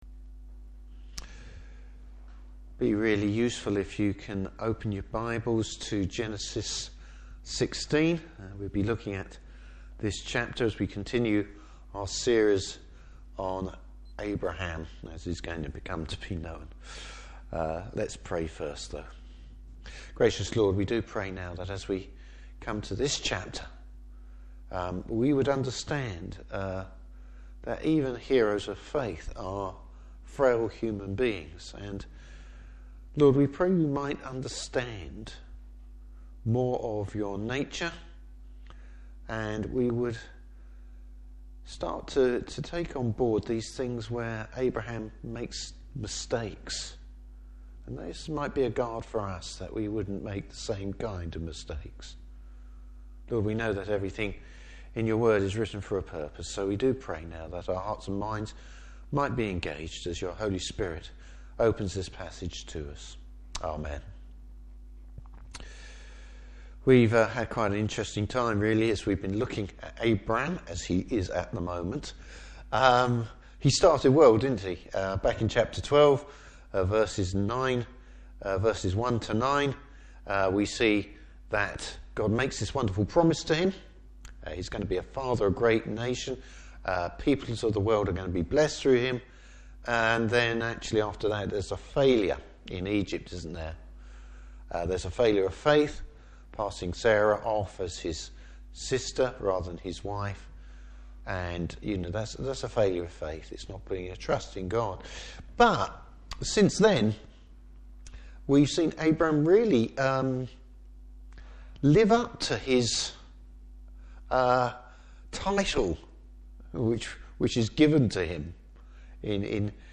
Service Type: Evening Service Abram’s failure to understand God’s timing and his grace!